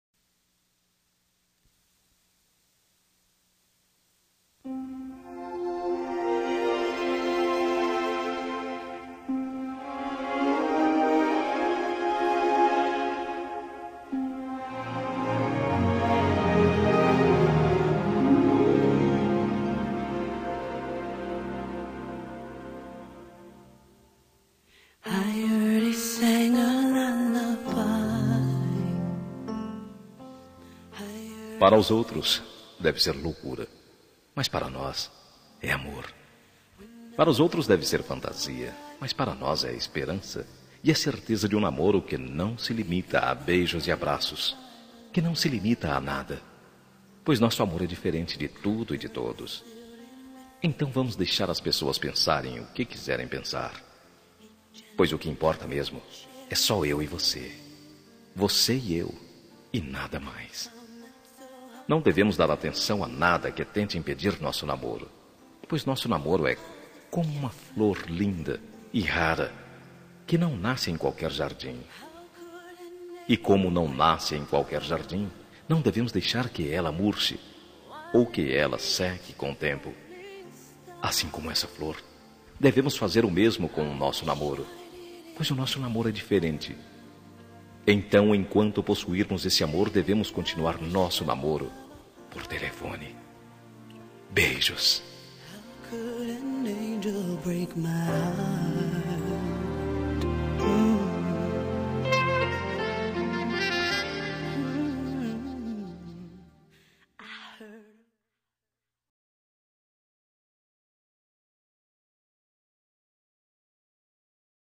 Telemensagem Virtual – Voz Masculina – Cód: 60208